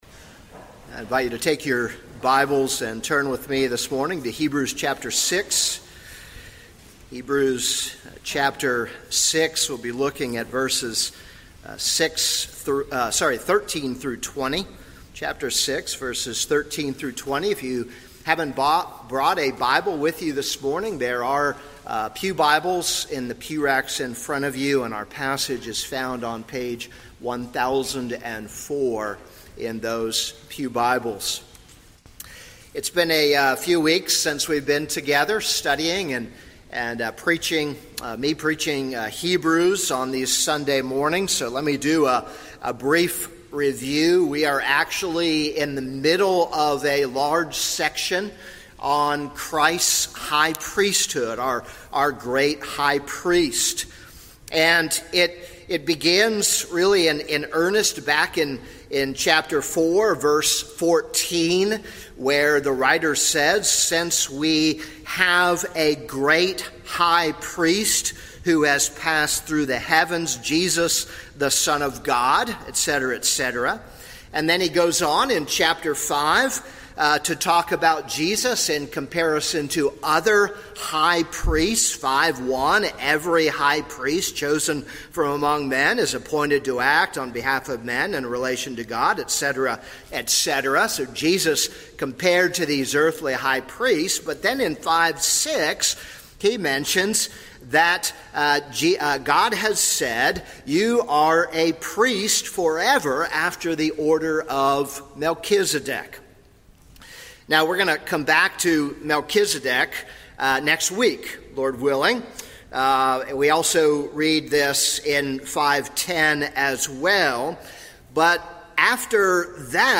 This is a sermon on Hebrews 6:13-20.